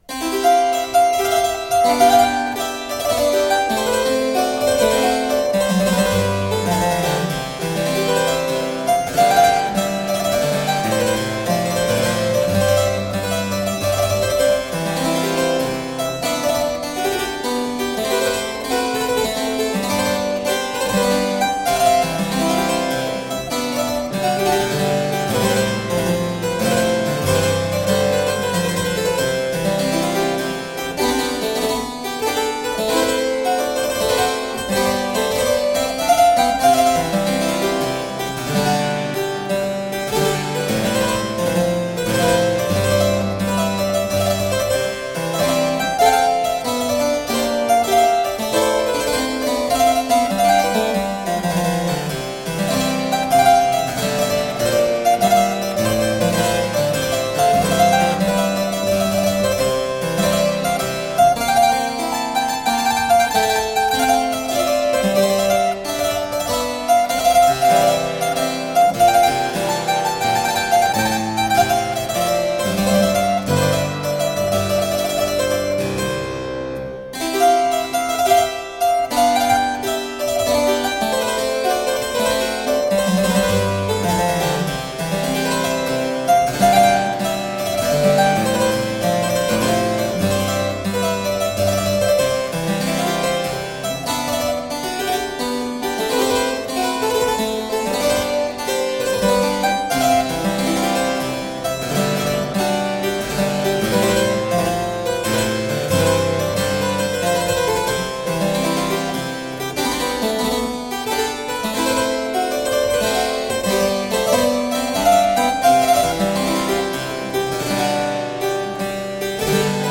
Deeply elegant harpsichord.